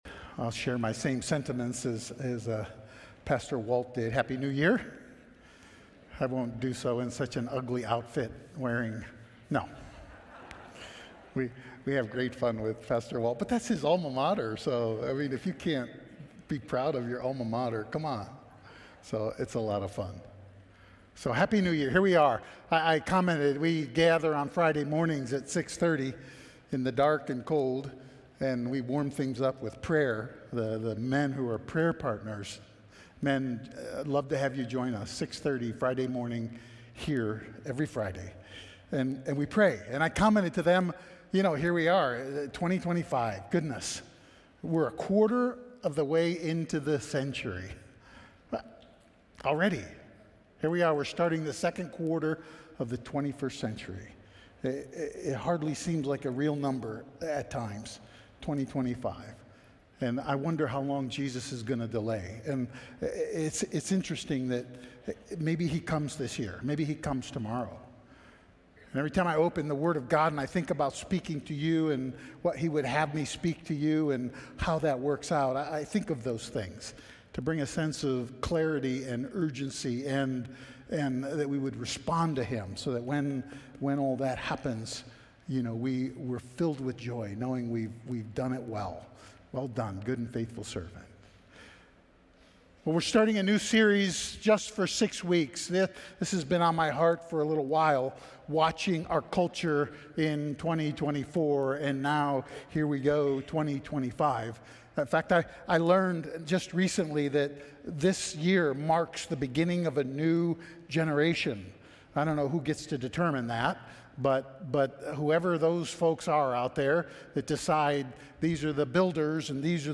We are Audio File Sermon Notes More From This Series All We Need 2025-02-16 His Covenant 2025-02-09 His Redemption 2025-02-02 His Design 2025-01-26 His Image 2025-01-19 His Creation 2025-01-12